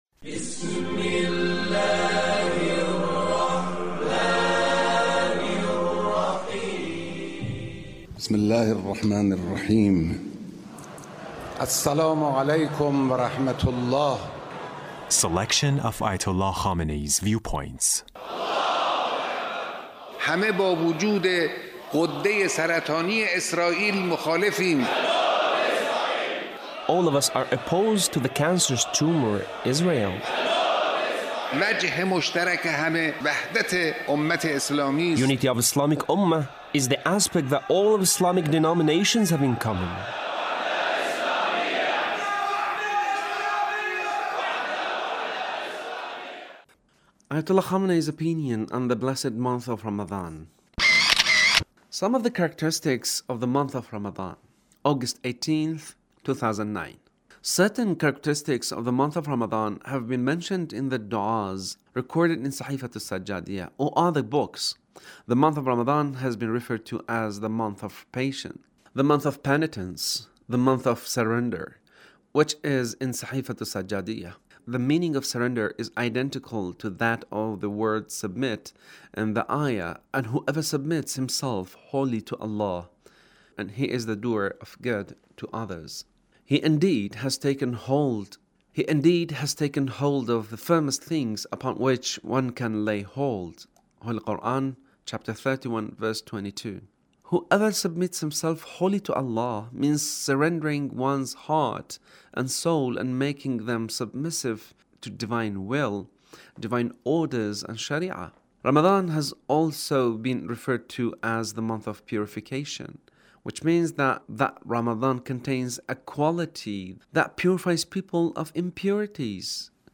Leader's Speech on Ramadhan